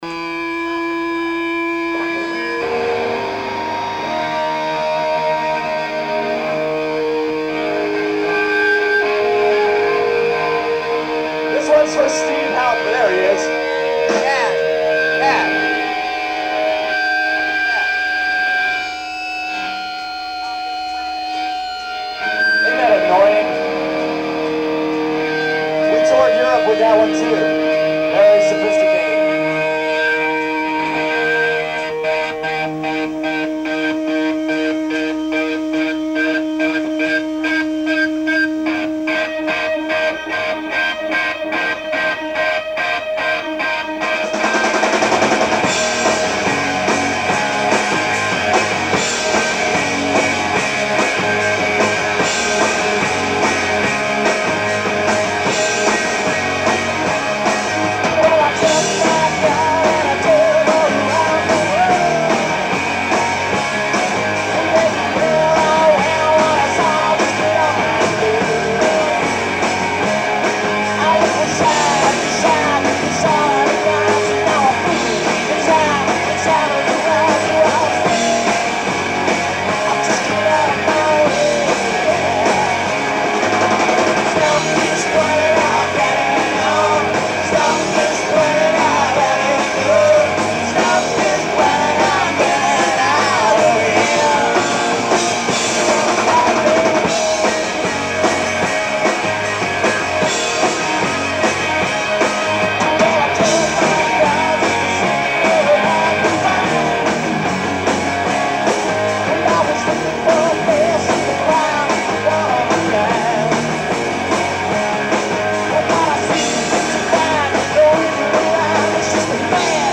Nick's tape
Upstairs At Nick’s, Philadelphia 6-16-95